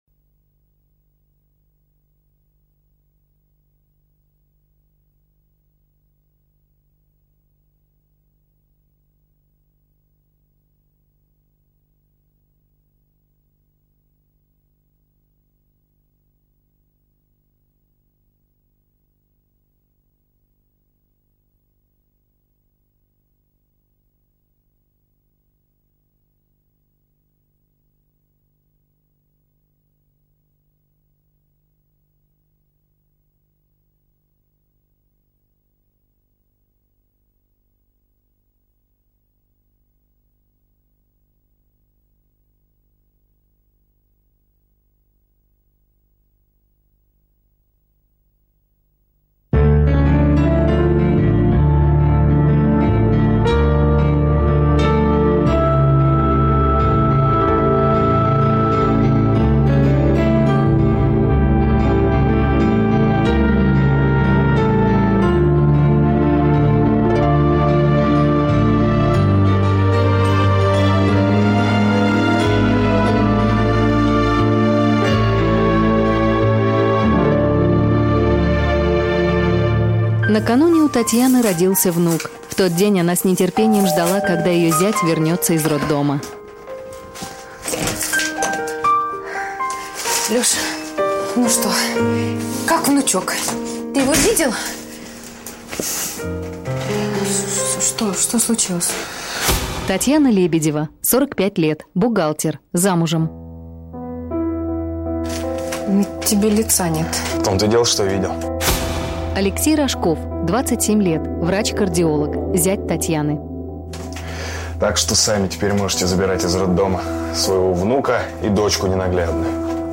Аудиокнига Ветер с востока | Библиотека аудиокниг